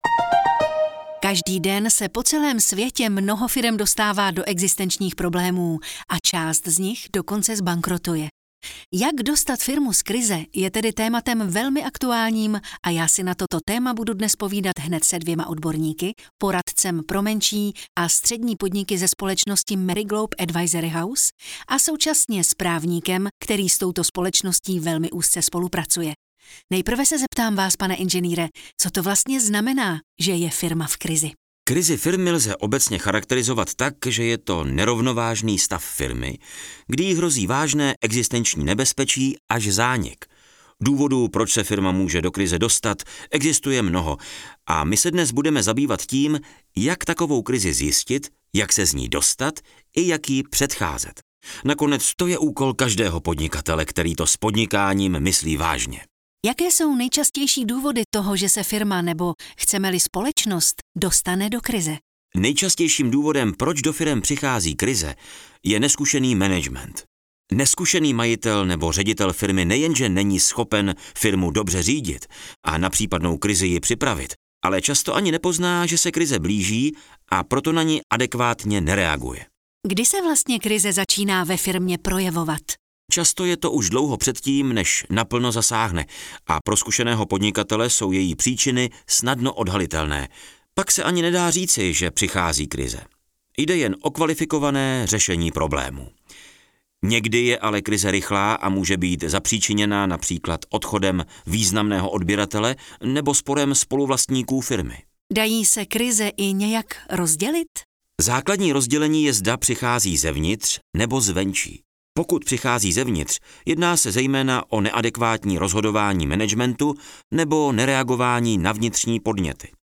AudioKniha ke stažení, 1 x mp3, délka 40 min., velikost 91,6 MB, česky